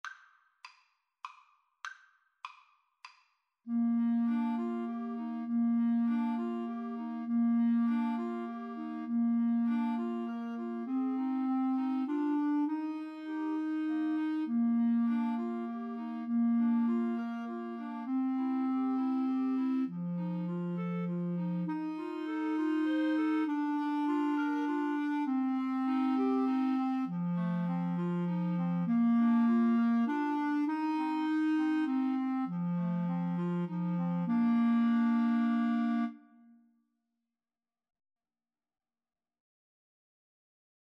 Bb major (Sounding Pitch) C major (Clarinet in Bb) (View more Bb major Music for Clarinet Trio )
Moderato
3/4 (View more 3/4 Music)
Clarinet Trio  (View more Easy Clarinet Trio Music)